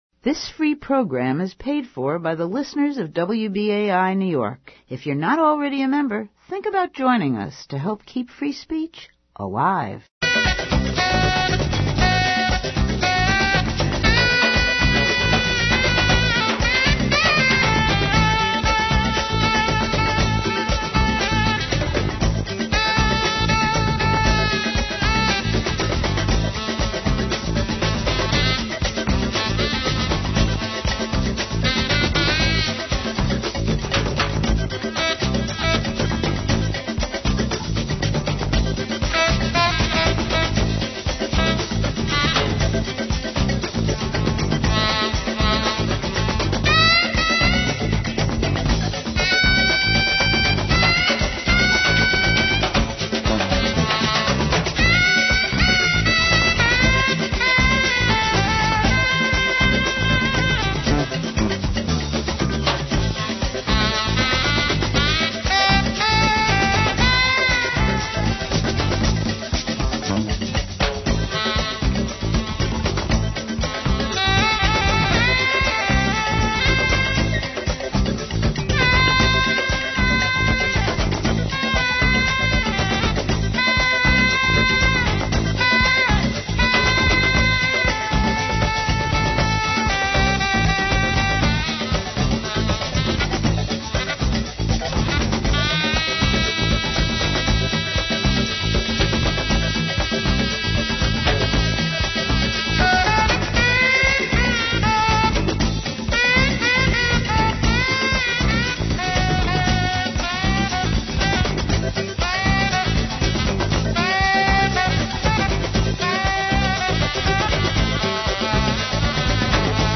Listen to the April 9, 2009 WBAI Radio show "Where We Live" focusing on the case of death row journalist Mumia Abu-Jamal, and the April 4 US Supreme Court ruling which rejected Abu-Jamal's appeal for a new guilt phase trial.